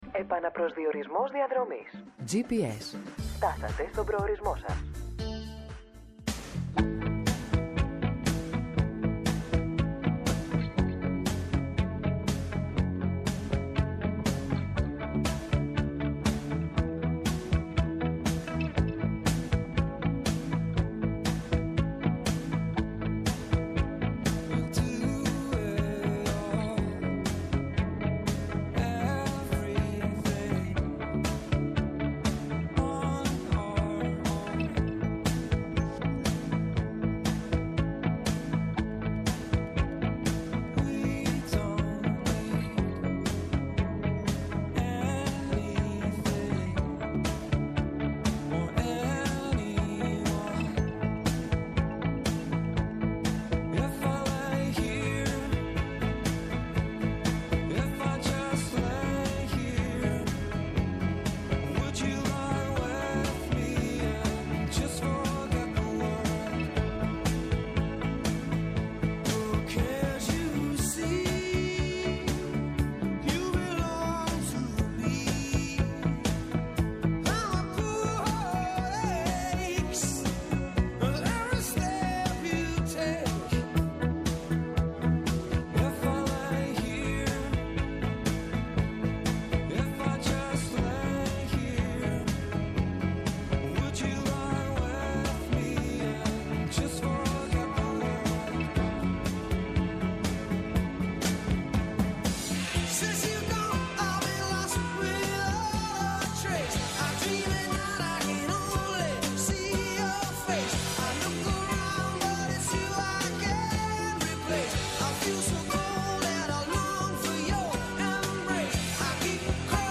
-Ο Δημήτρης Καιρίδης, υπουργός Μετανάστευσης και Ασύλου,καθηγητής Διεθνών Σχέσεων στο Πάντειο Πανεπιστήμιο
-o Κώστας Μποτόπουλος, συνταγματολόγος, πρώην ευρωβουλευτής